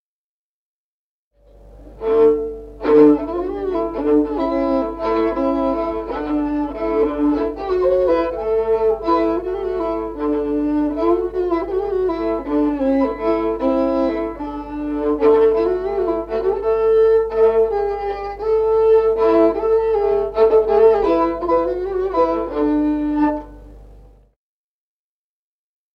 Музыкальный фольклор села Мишковка «На Бога гляньте», свадебная, партия 2-й скрипки.